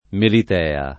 [ melit $ a ]